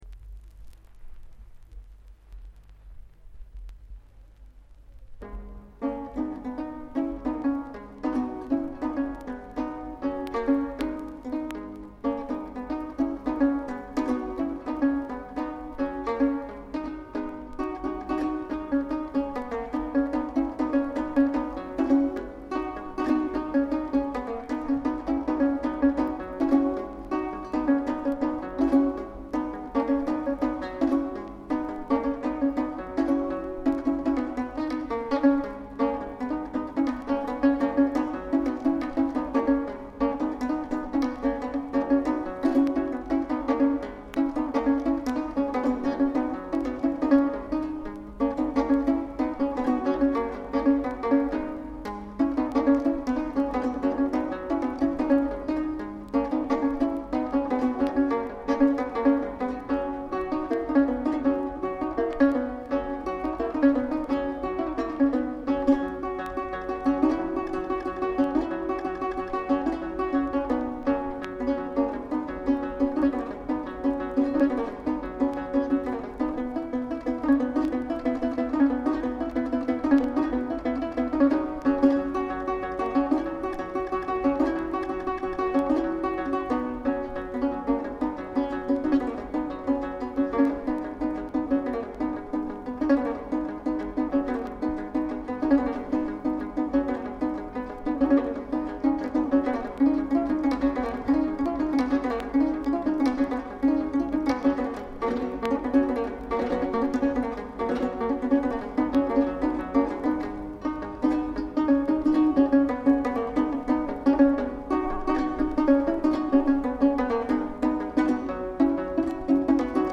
საკვანძო სიტყვები: ქართული ხალხური სიმღერა